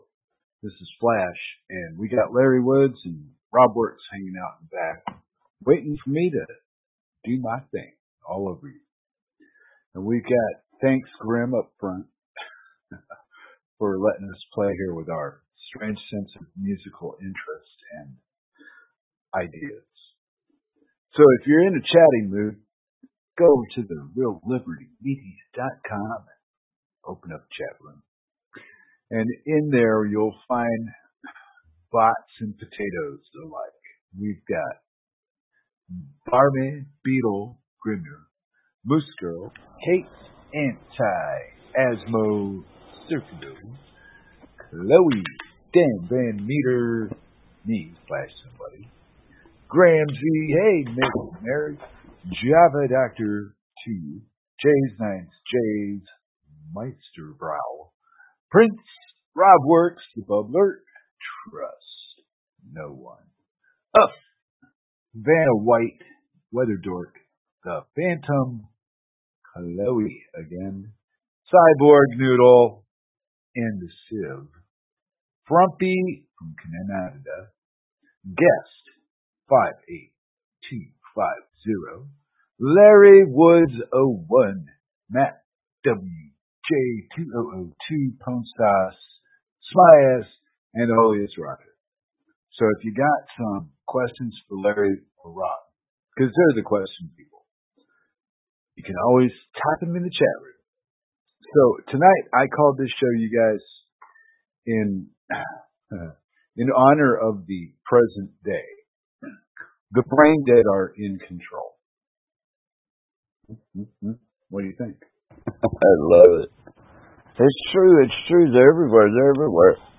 Genre Talk